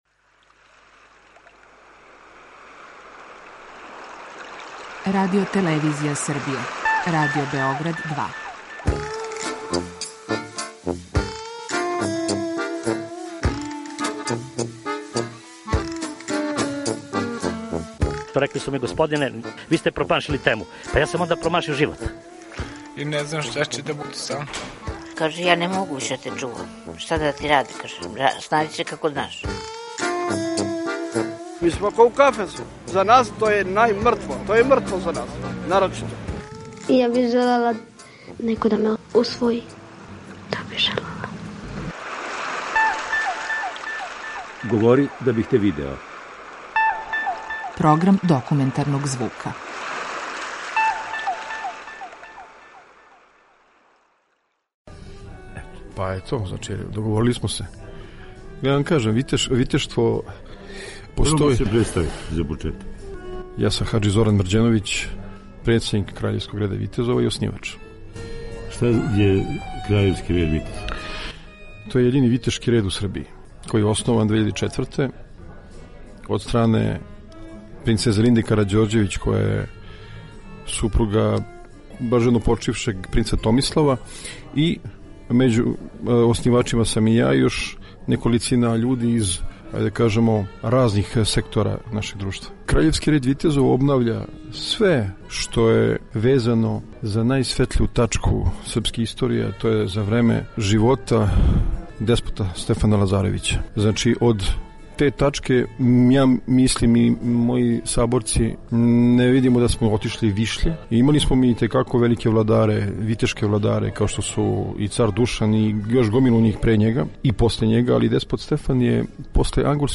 Серија полусатних документарних репортажа